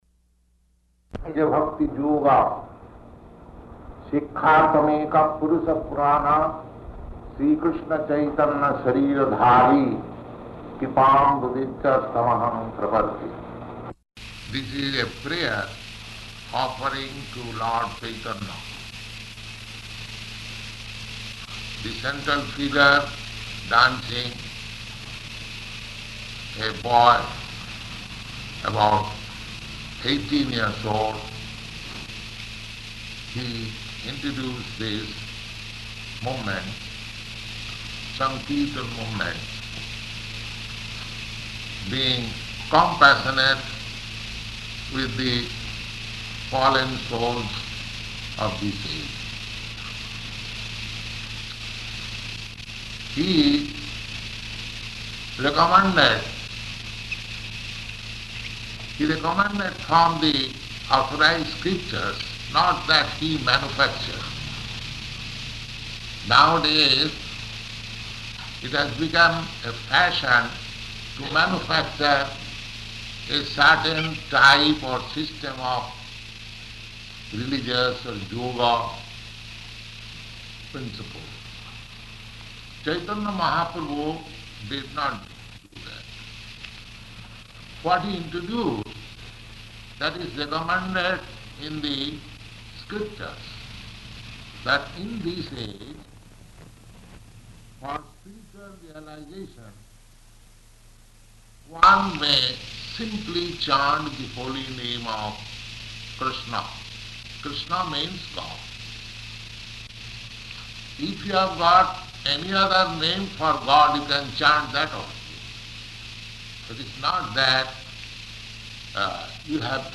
Location: Los Angeles